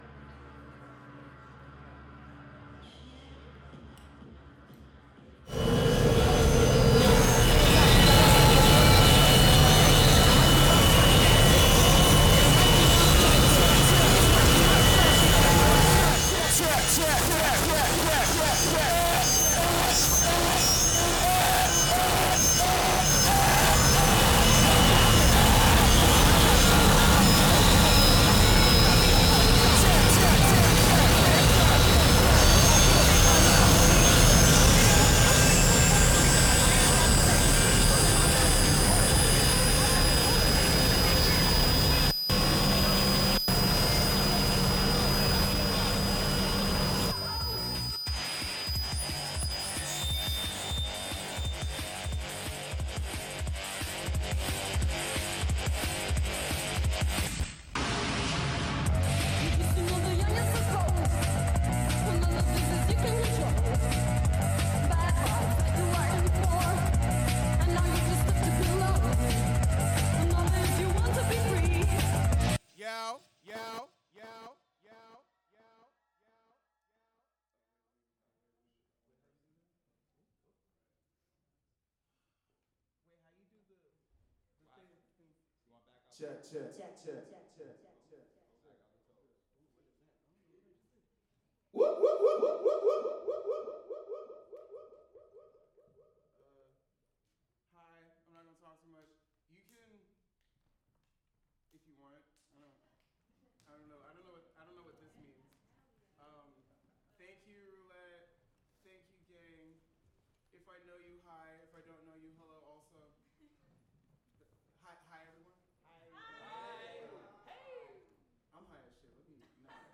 who describes the project as ‘nihilist queer revolt musik.’
Genres: Electronic , New Music , Noise